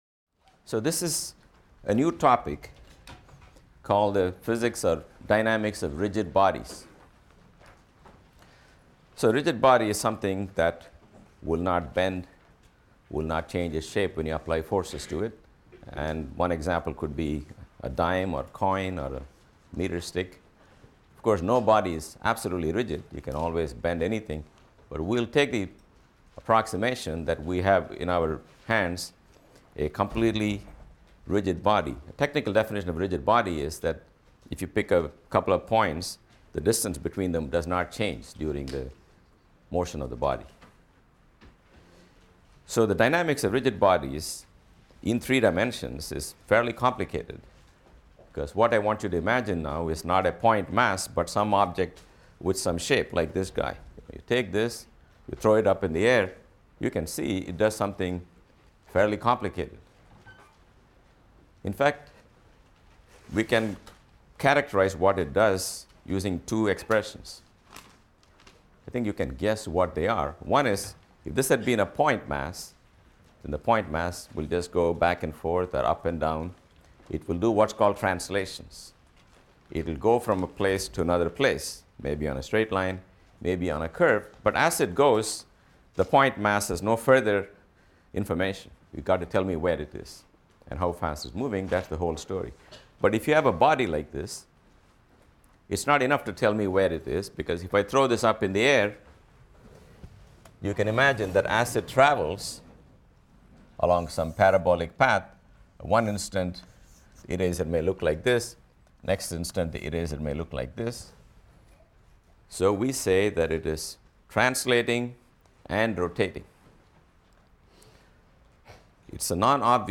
PHYS 200 - Lecture 9 - Rotations, Part I: Dynamics of Rigid Bodies | Open Yale Courses